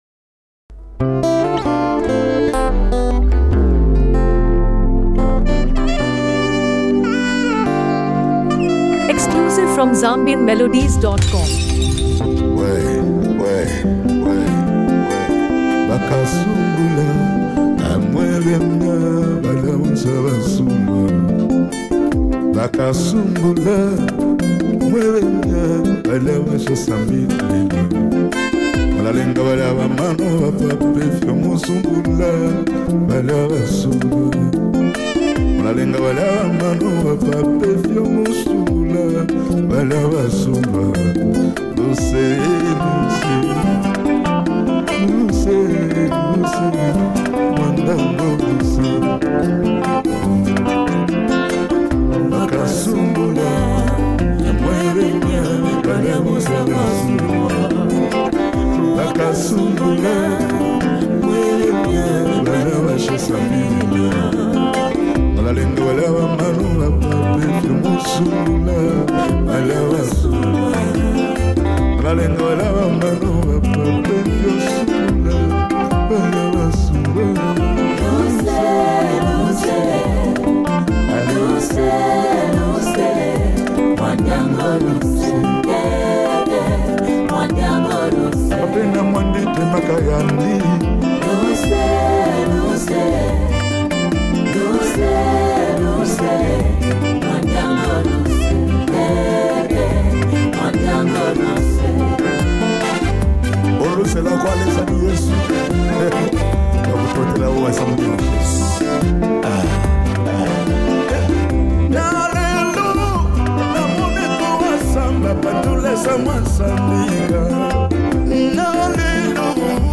Zambia Gospel
and prayerful atmosphere.